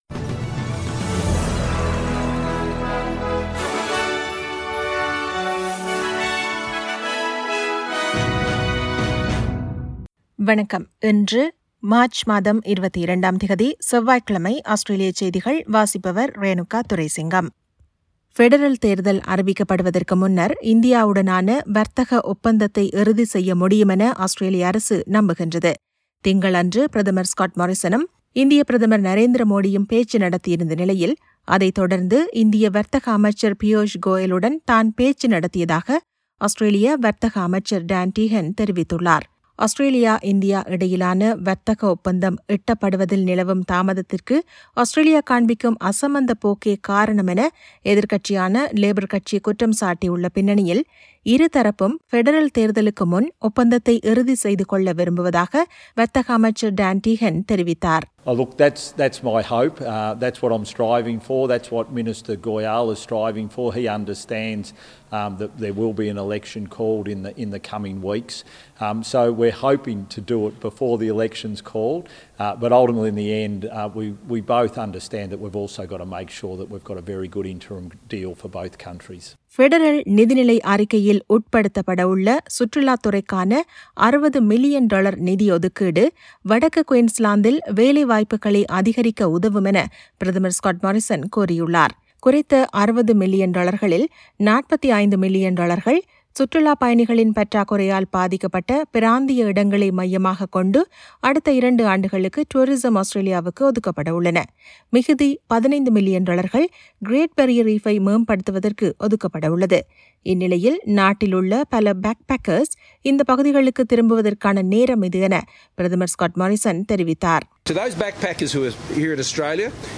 Australian news bulletin for Tuesday 22 March 2022.